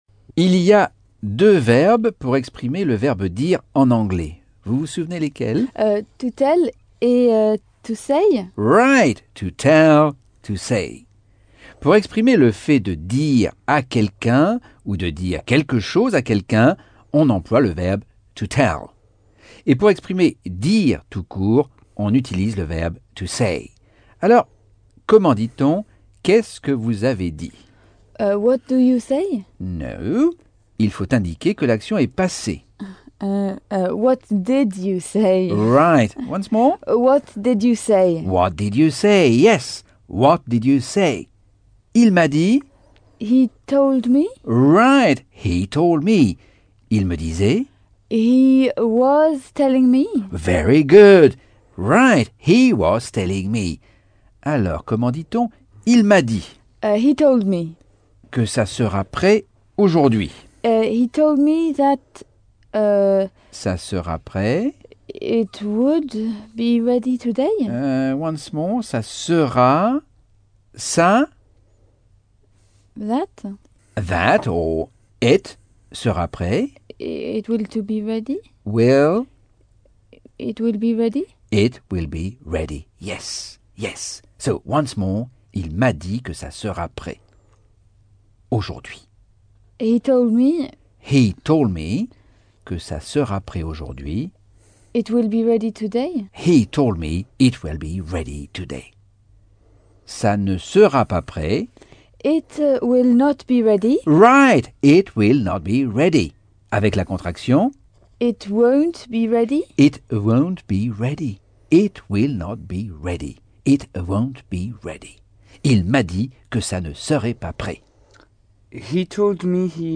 Leçon 3 - Cours audio Anglais par Michel Thomas - Chapitre 8